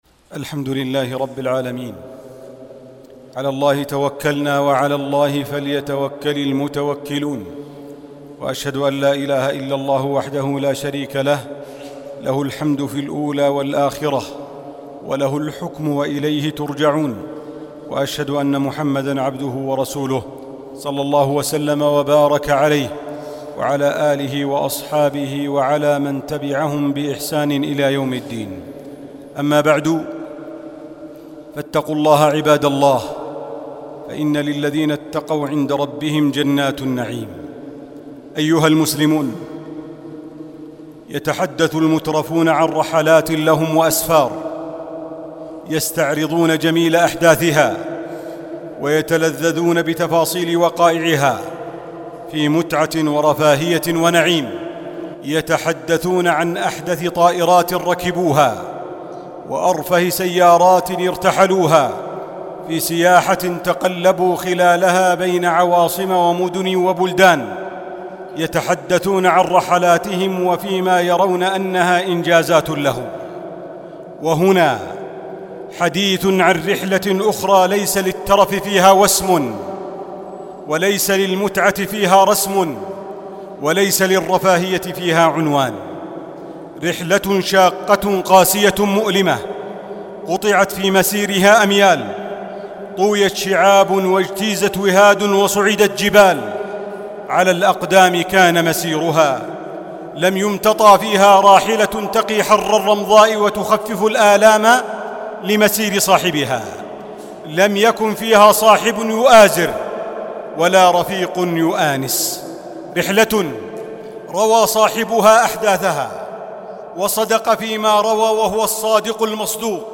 الخطب الصوتية